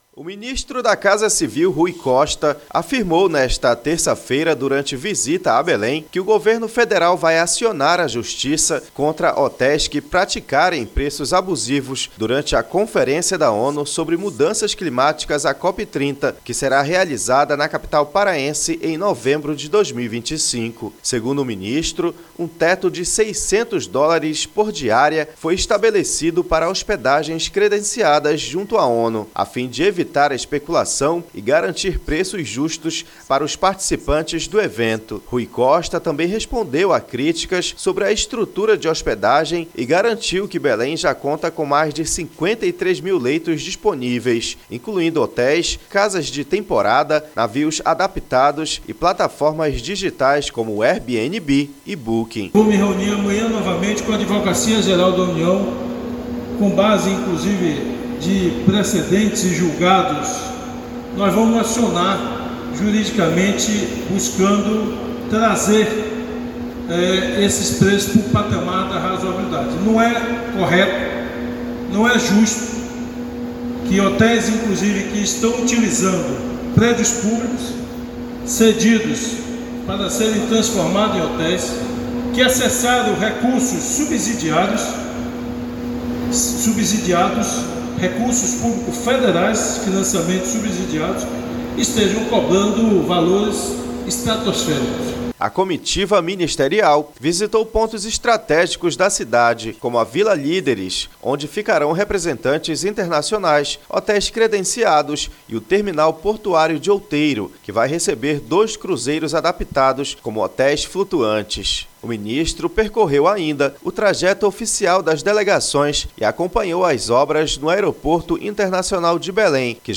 0---COLETIVA-COP-30.mp3